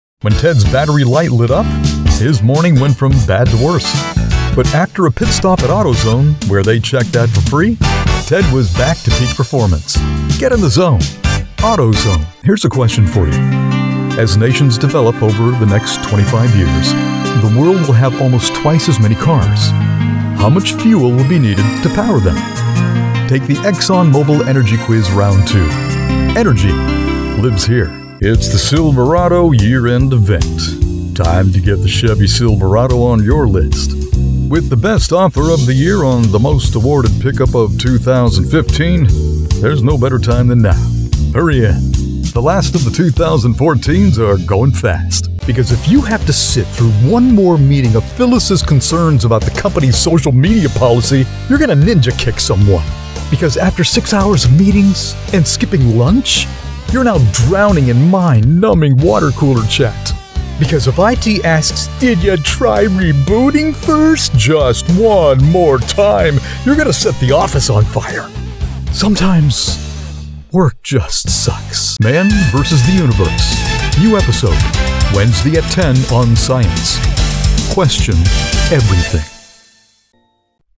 Male
Adult (30-50), Older Sound (50+)
Deep, Rich, Smooth, the perfect blend of voice. From Conversational to DJ sound, including, but not limited to, Southern, Pirate and Old Man Voices.
Main Demo
Words that describe my voice are Deep, Natural, Conversational.